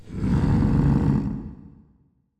roar02.m4a